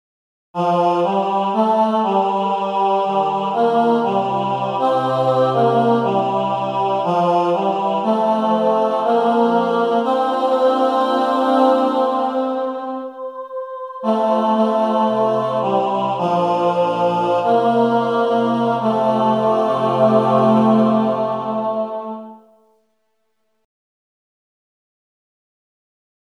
Key written in: F Major